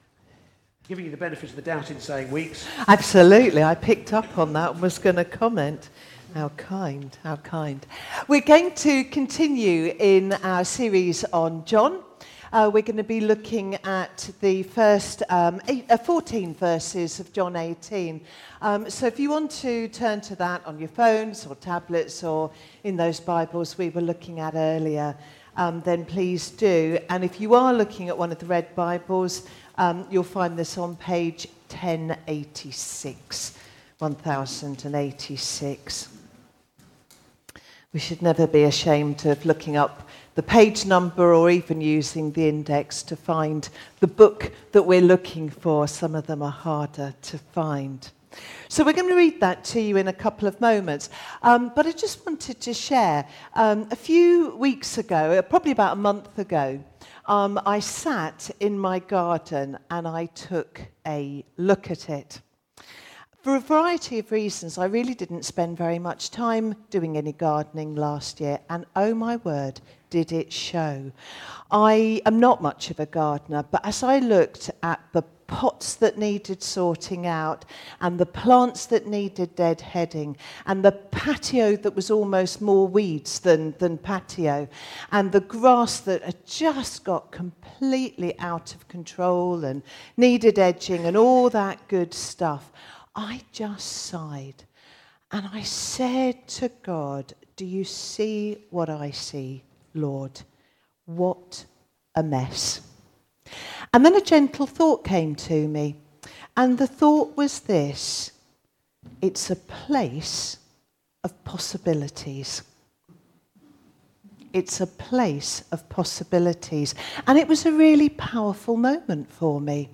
Talks and Sermons - Thornhill Baptist Church